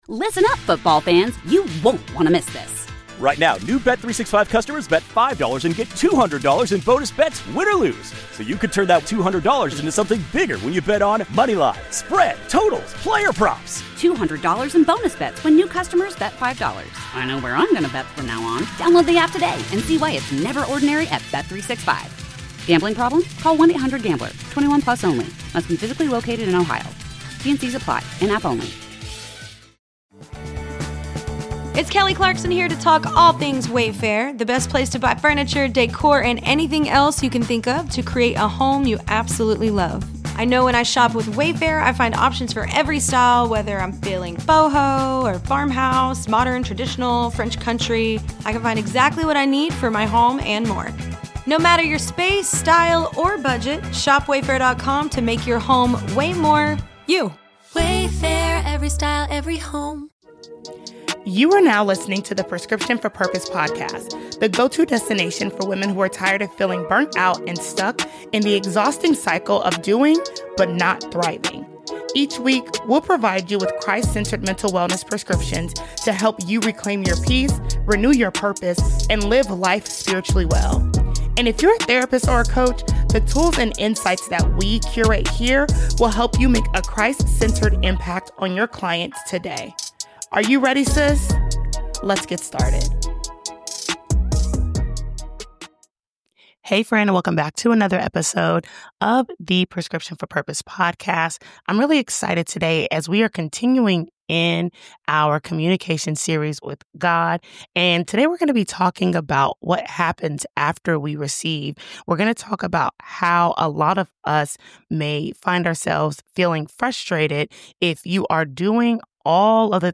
A revolution is brewing in hospitality leadership, and it begins with a radical notion: your most sacred guest is you. This powerful conversation